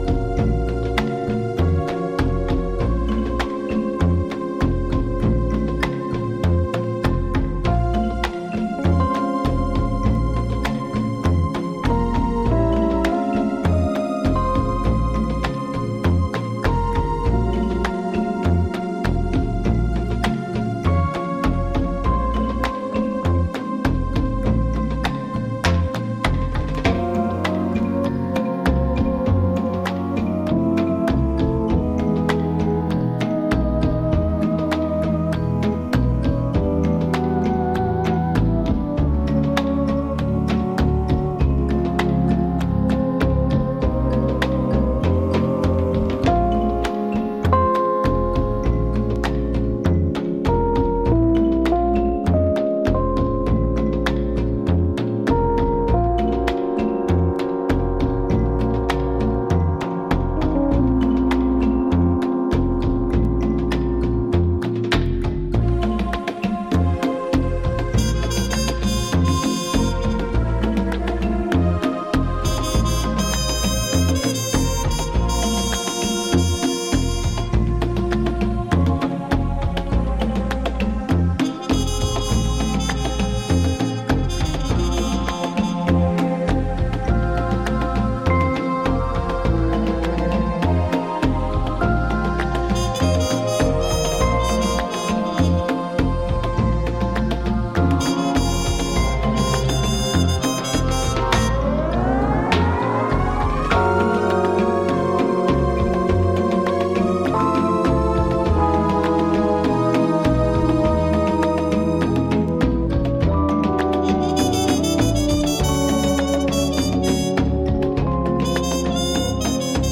tropical balearica of midnight with muted trumpet mood